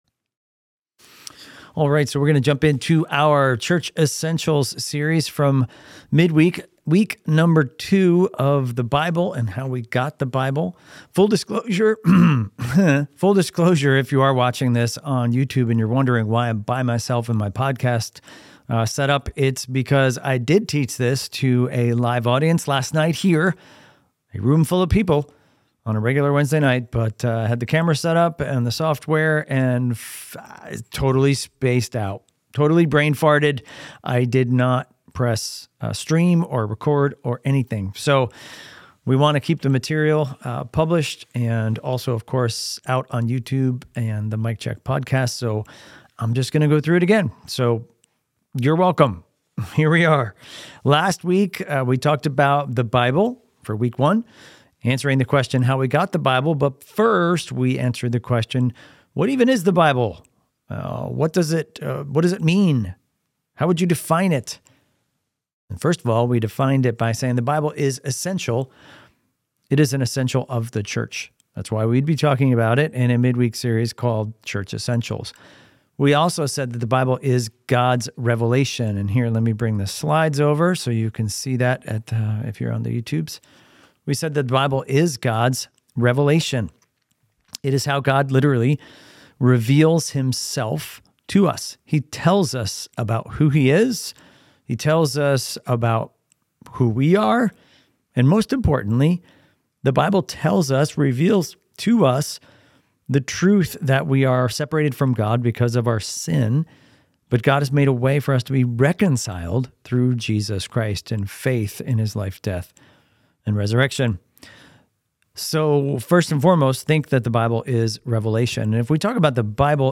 Highlands Bible Church Sermon Audio